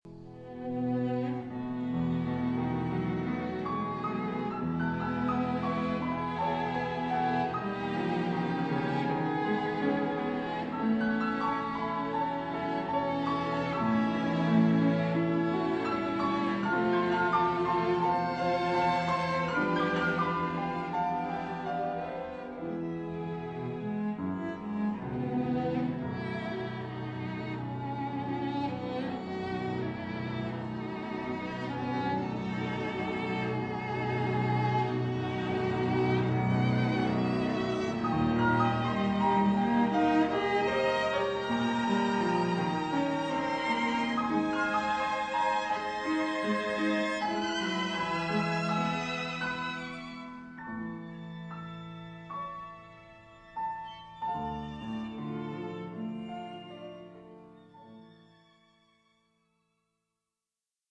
Piano Quintet in D Major – second subject of first movement
Above is the opening of the second subject of the first movement.